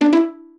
jingles-pizzicato_06.ogg